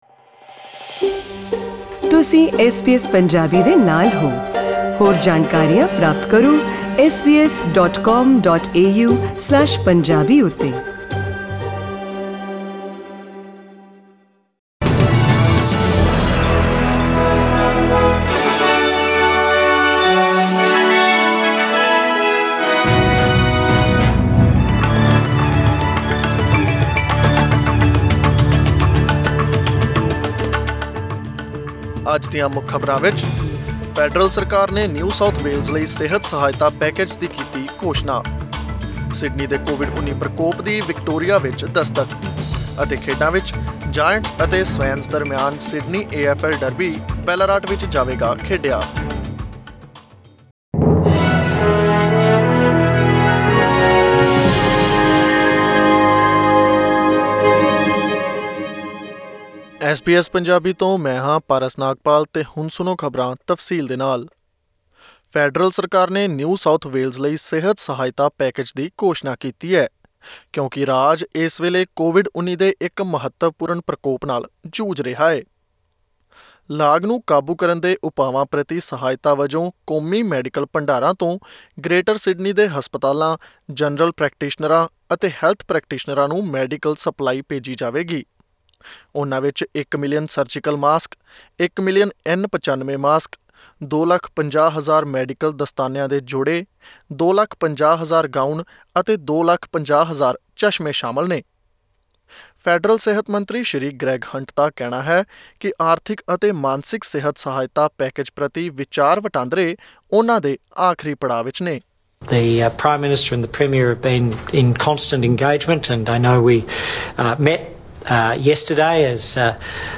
Listen to the latest news headlines in Australia from SBS Punjabi radio.
Click on the player at the top of the page to listen to the news bulletin in Punjabi.